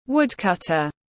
Thợ xẻ gỗ tiếng anh gọi là woodcutter, phiên âm tiếng anh đọc là /ˈwʊd.kʌt.ər/.
Woodcutter /ˈwʊd.kʌt.ər/